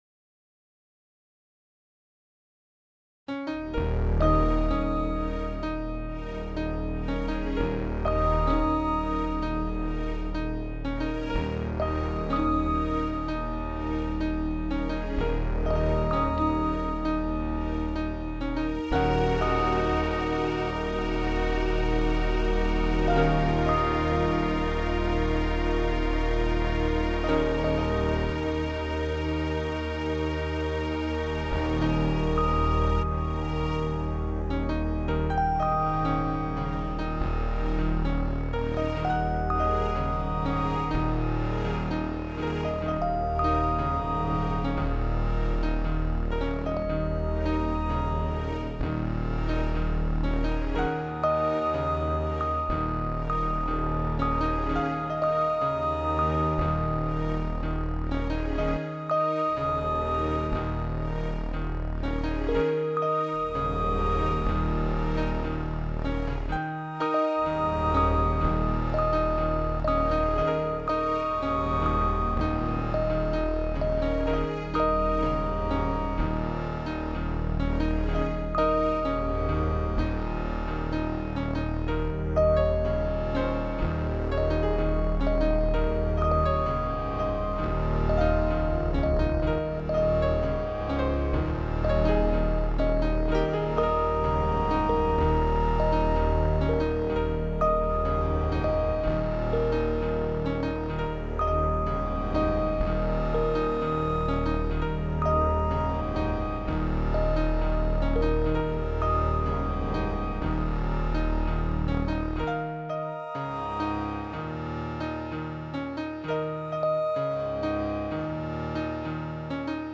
Tune I made for Video Game, melodic style